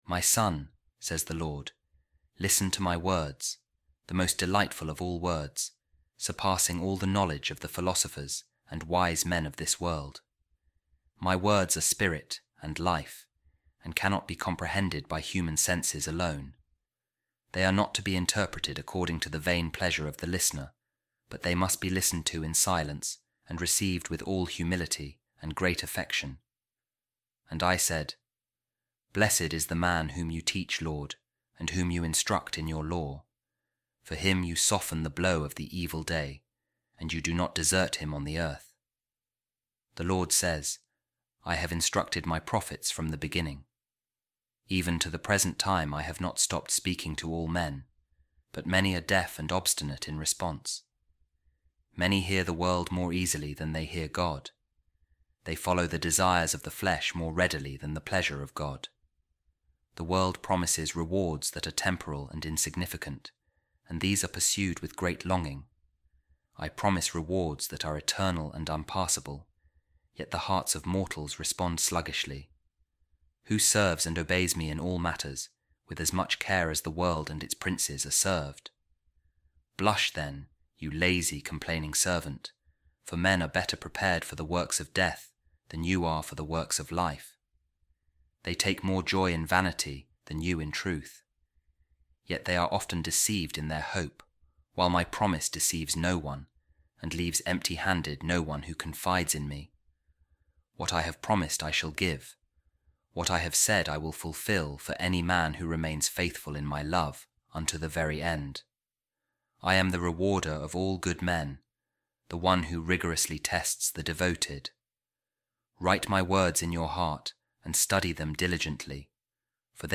A Reading From The Imitation Of Christ | I Have Taught My Prophets
office-reading-monday-22-imitation-christ.mp3